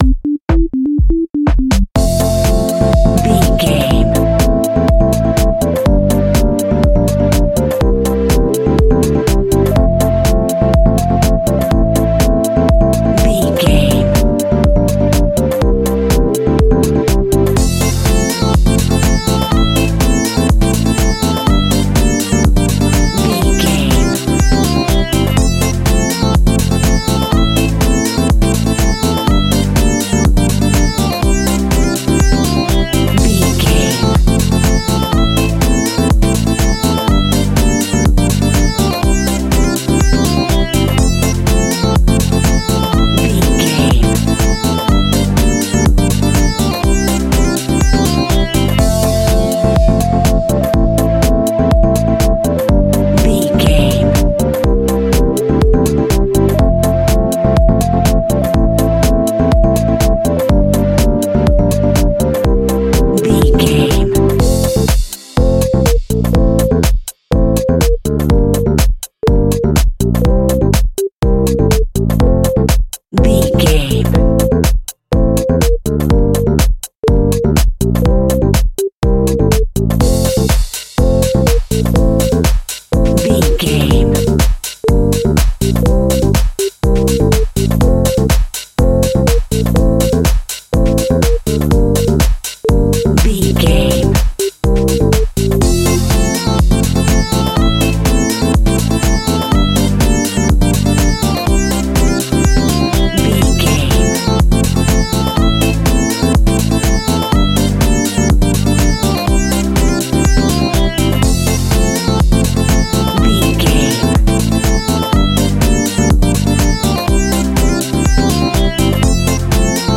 Ionian/Major
groovy
uplifting
energetic
electric guitar
horns
funky house
disco
upbeat
funky guitar
clavinet
synth bass